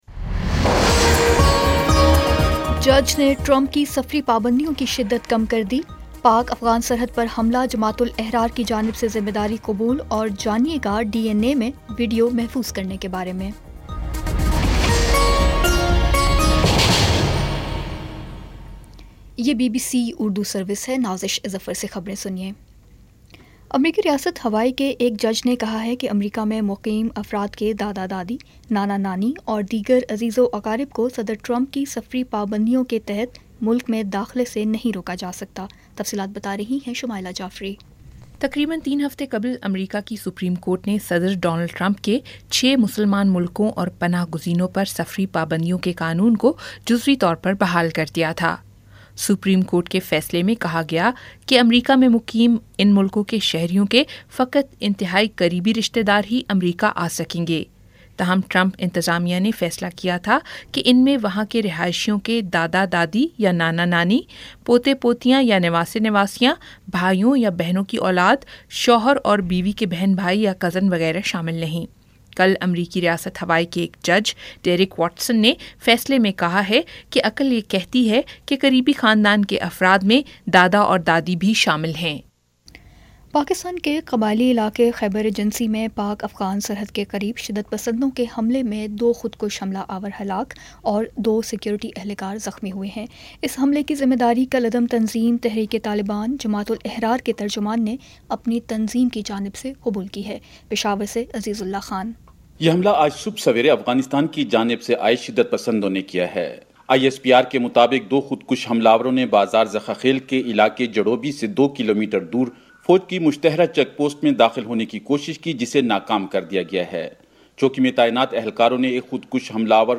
جولائی 14 : شام پانچ بجے کا نیوز بُلیٹن